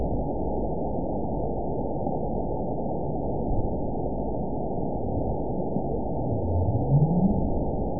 event 922896 date 05/01/25 time 21:09:59 GMT (1 month, 2 weeks ago) score 9.63 location TSS-AB02 detected by nrw target species NRW annotations +NRW Spectrogram: Frequency (kHz) vs. Time (s) audio not available .wav